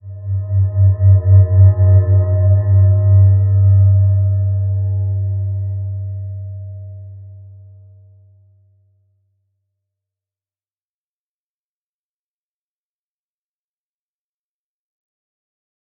Slow-Distant-Chime-G2-f.wav